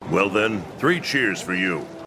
Download Three Cheers sound effect for free.